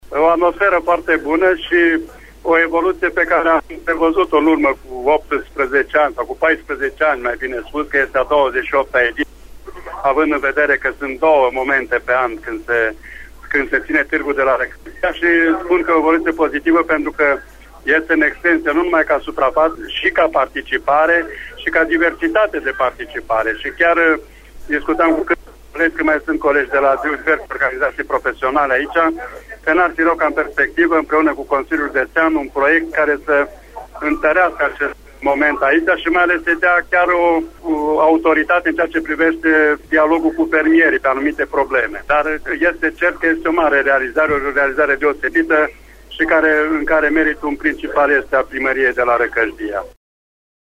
El a vorbit în exclusivitate pentru Radio România Reşiţa despre atmosfera de la târg: